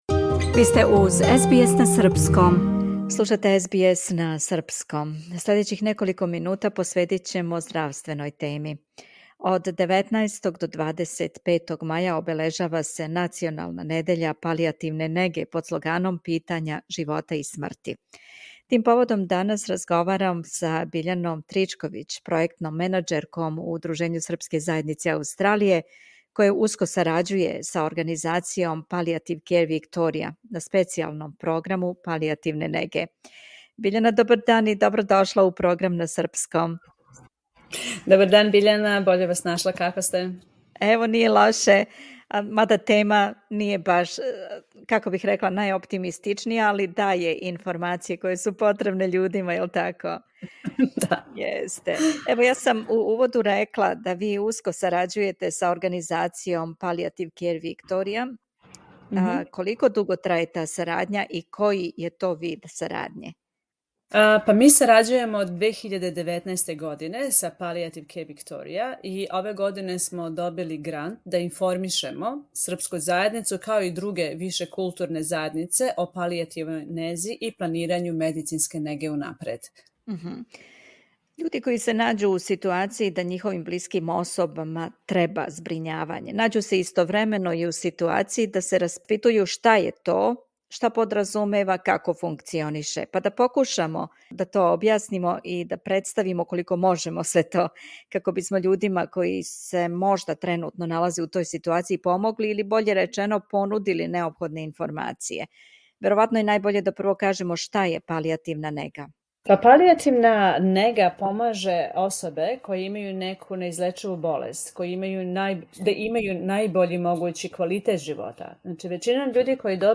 СБС на српском